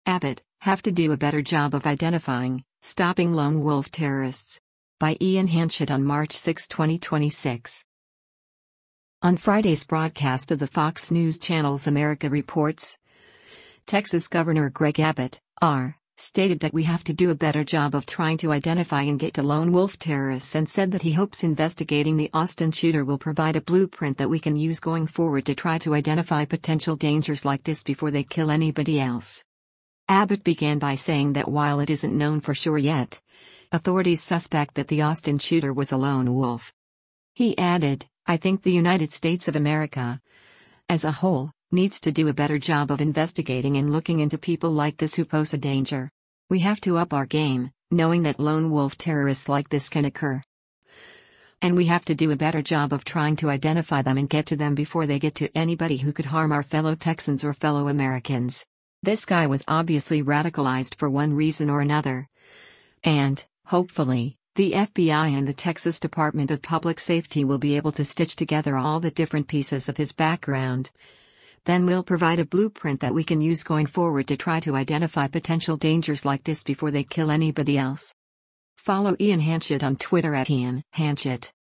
On Friday’s broadcast of the Fox News Channel’s “America Reports,” Texas Gov. Greg Abbott (R) stated that “we have to do a better job of trying to identify” and get to lone wolf terrorists and said that he hopes investigating the Austin shooter “will provide a blueprint that we can use going forward to try to identify potential dangers like this before they kill anybody else.”